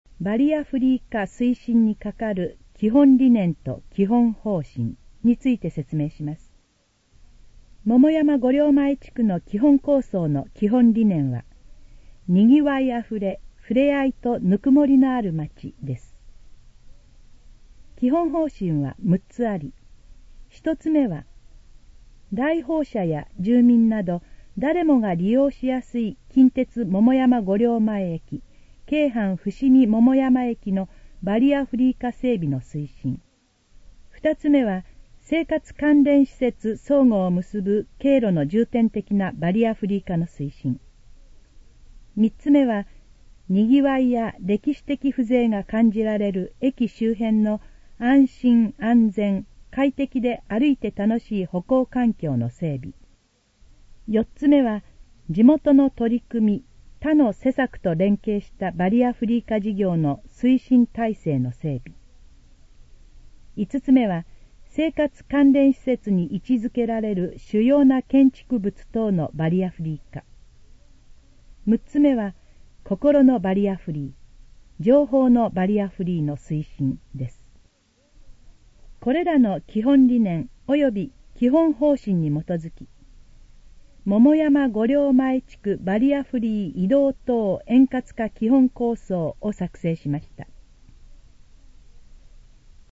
このページの要約を音声で読み上げます。
ナレーション再生 約363KB